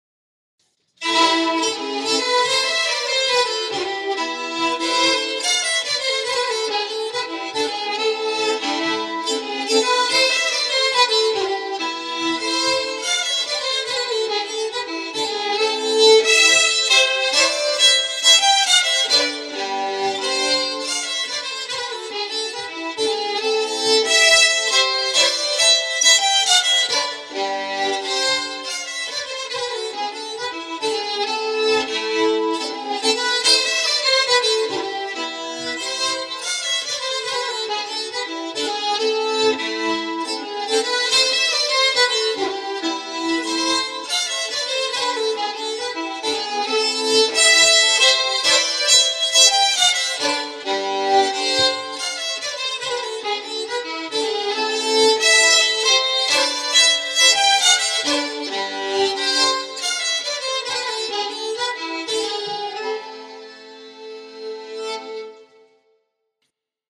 När jag kom hem igen så spelade jag in samma låt på min dator för att visa hur balansen mellan 1:a o 2:a stämman ska mixas.